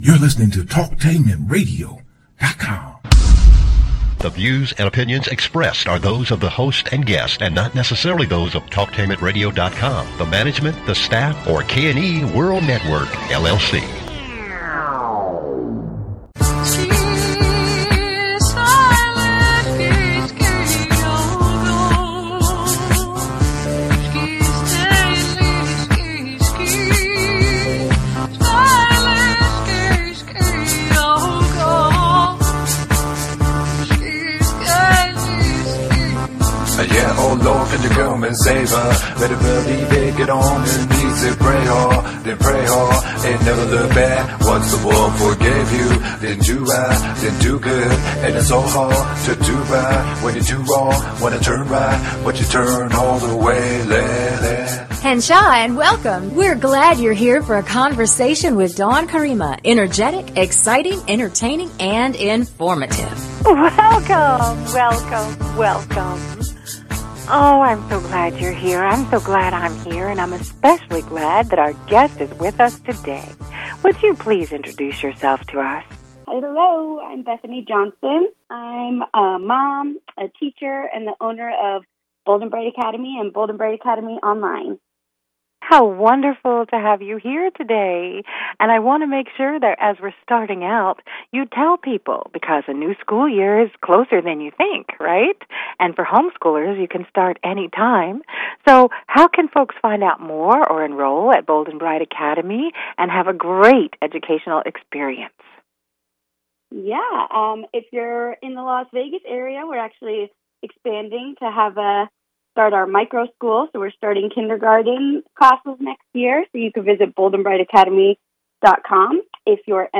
This online radio broadcast features interviews with some of today’s most fascinating and talented Native American artists, authors, musicians, educations, media professionals and scholars.